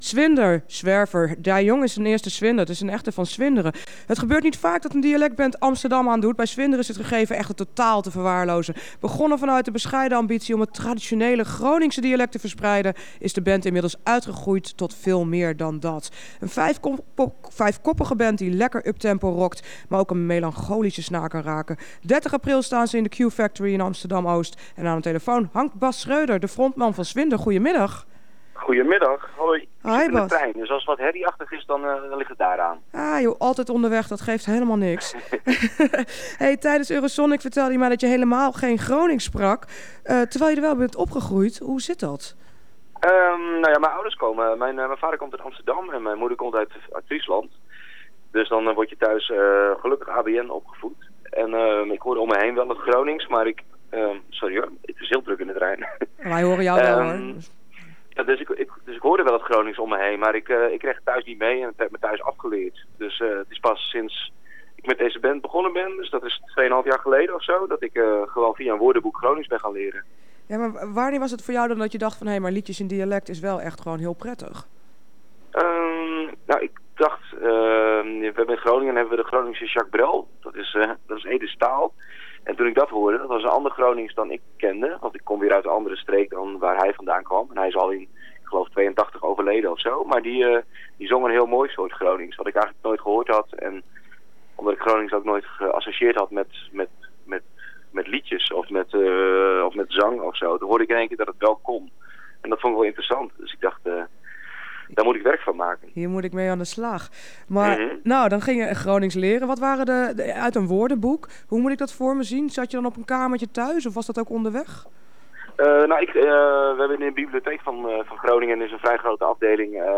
Een vijfkoppige band die lekker uptempo rockt maar ook een melancholische snaar kan raken.